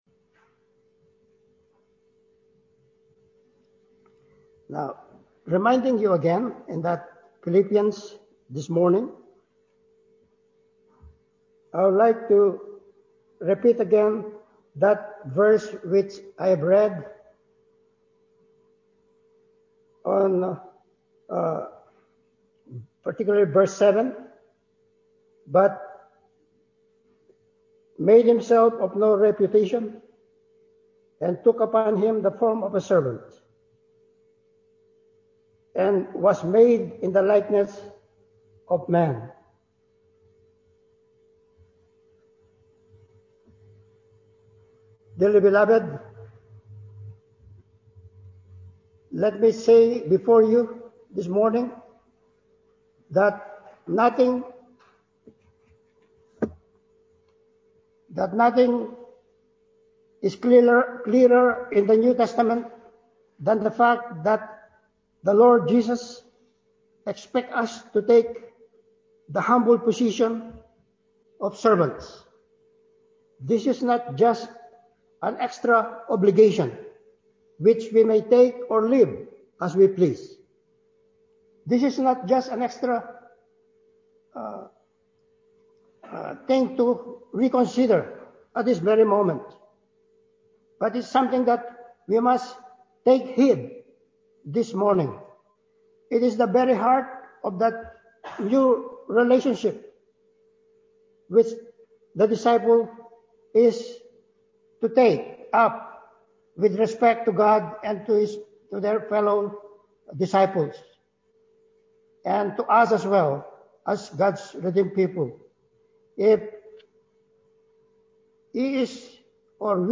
Passage: Philippians 2:5-8 Service Type: Sunday Morning Service